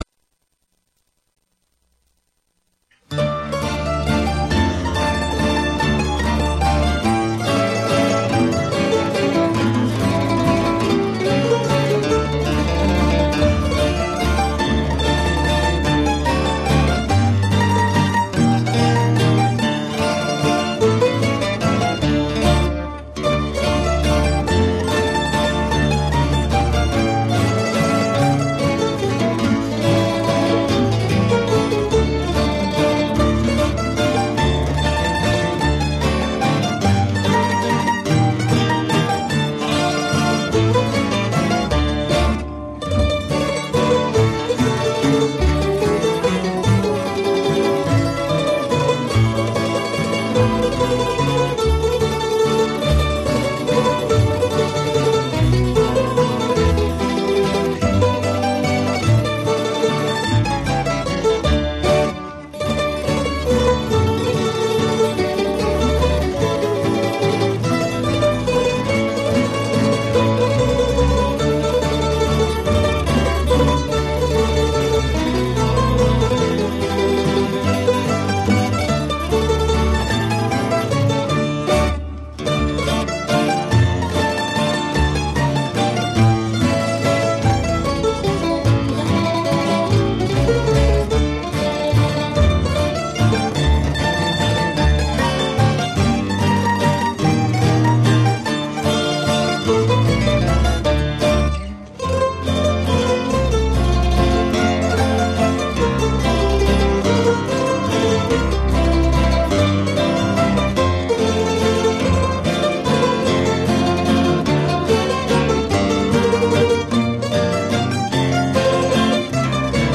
mandocello